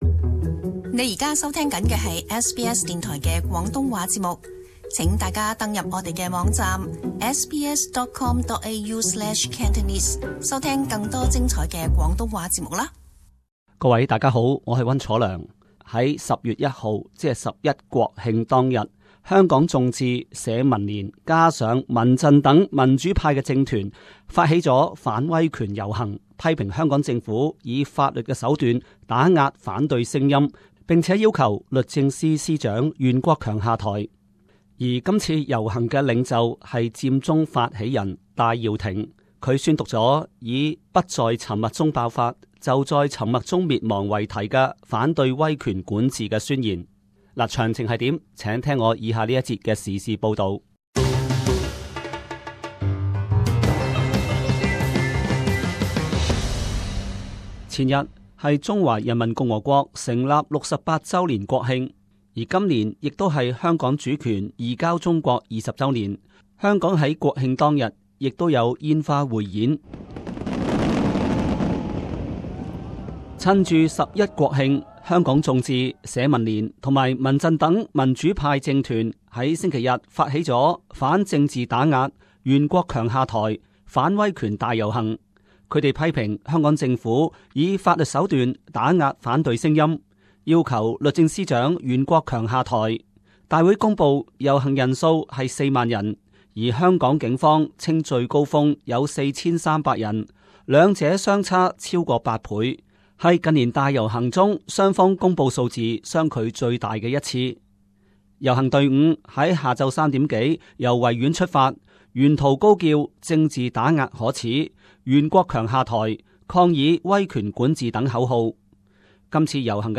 SBS廣東話節目